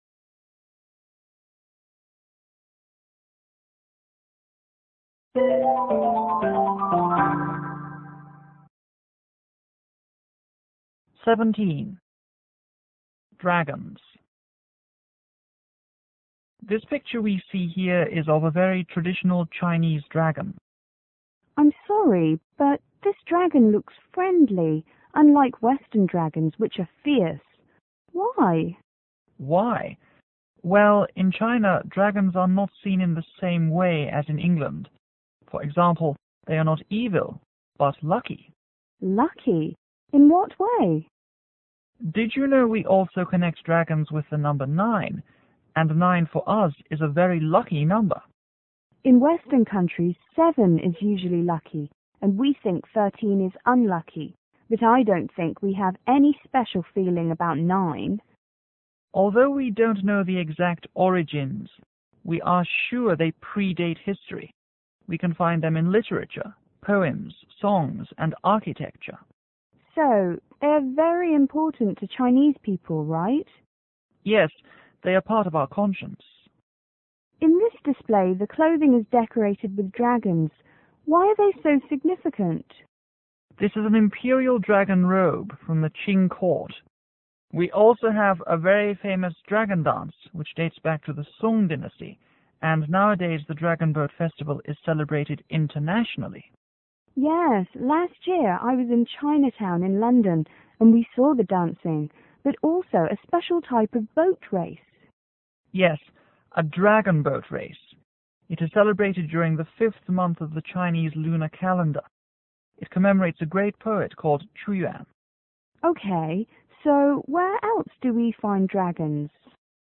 M: Museum curator     V: Visitor